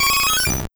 Cri d'Évoli dans Pokémon Or et Argent.